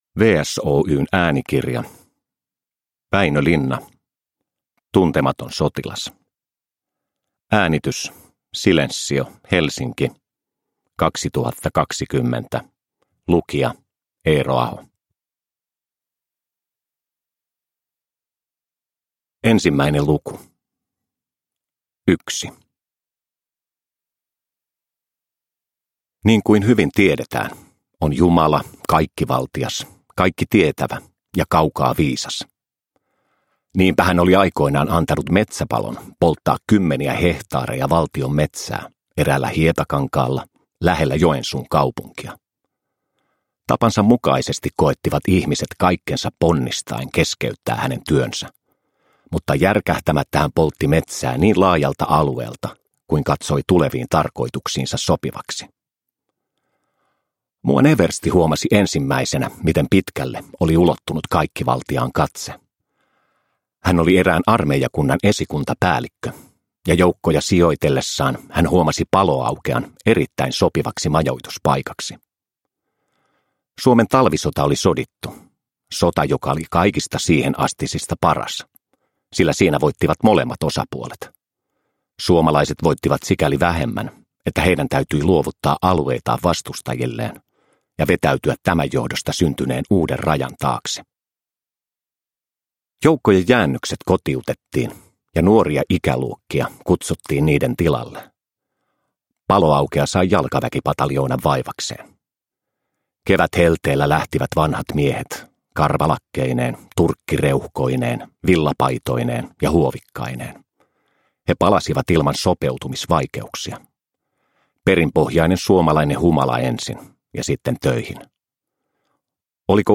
Tuntematon sotilas – Ljudbok – Laddas ner
Juhlavuoden kunniaksi WSOY julkaisee Tuntemattomasta sotilaasta äänikirjan näyttelijä Eero Ahon lukemana.
Uppläsare: Eero Aho